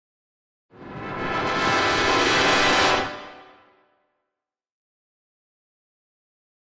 Brass Section, Tension Crescendo - "Danger Approach", Type 3